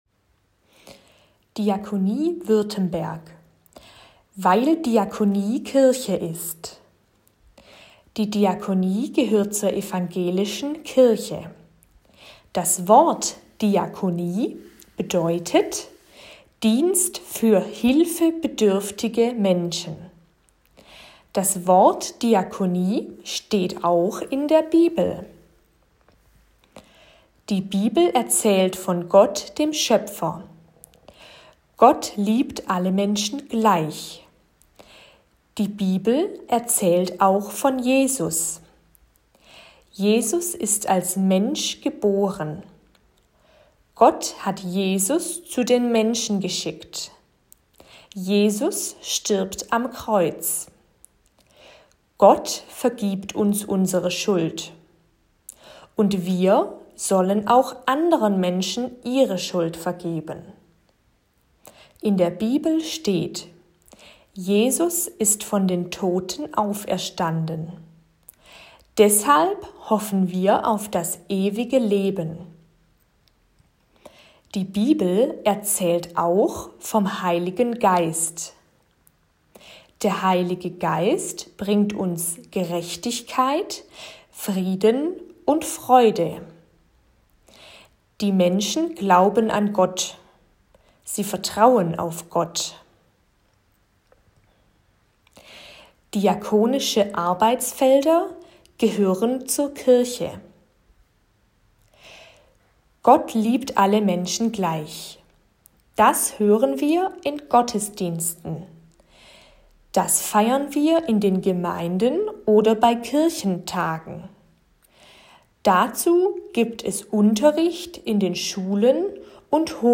Kirchen- und Diakoniebild, auch in leichter Sprache und als Audioaufnahme.
Pocketformat_Kirche_ist_Diakonie_Leichte_Sprache.m4a